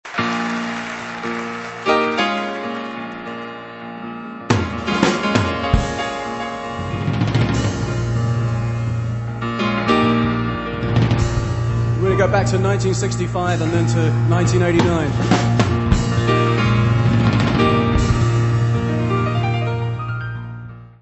piano
baixo
bateria.
Music Category/Genre:  Pop / Rock